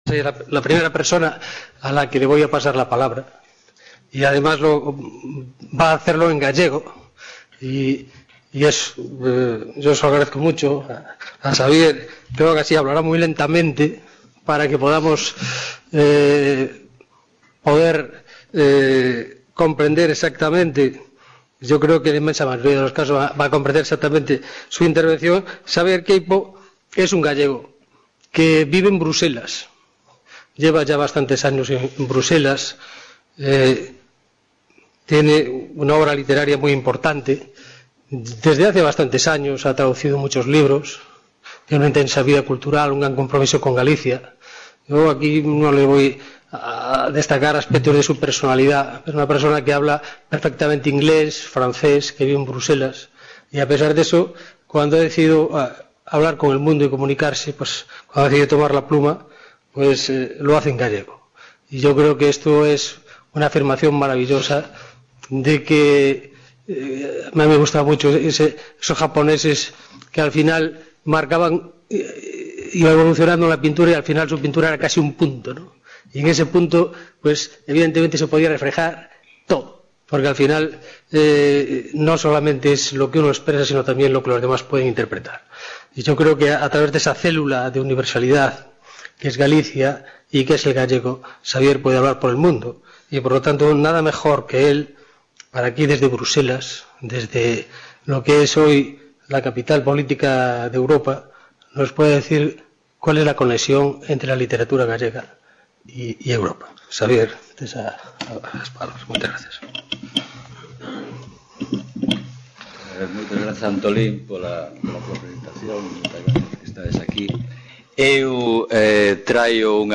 Mesa redonda: Literatura gallega y Europa | Repositorio Digital
Reunion, debate, coloquio...